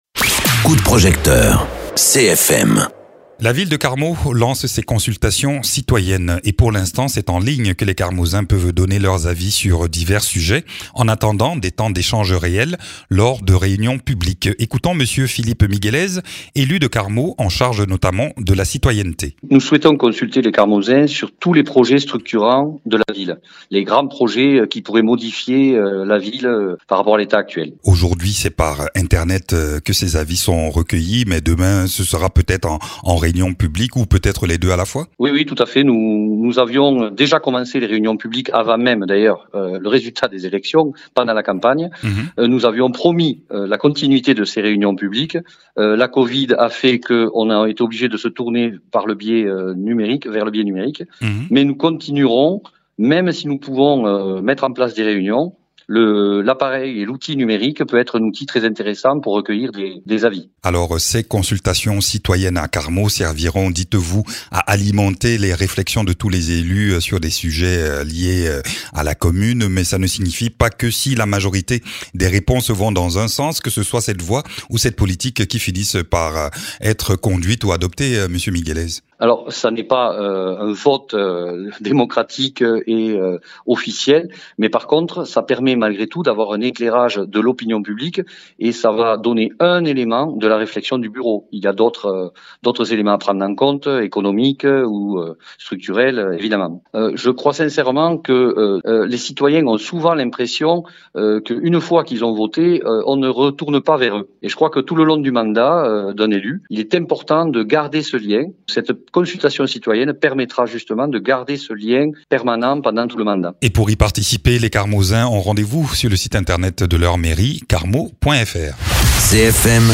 Interviews
Invité(s) : Philippe Miguelez, adjoint à la communication, citoyenneté et vie associative.